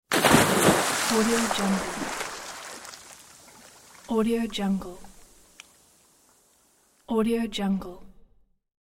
دانلود افکت صدای افتادن توپ داخل استخر
افکت صدای افتادن توپ داخل استخر یک گزینه عالی برای هر پروژه ای است که به صداهای کارتونی و جنبه های دیگر مانند شنا، استخر و آب پاش نیاز دارد.
Sample rate 16-Bit Stereo, 44.1 kHz
Looped No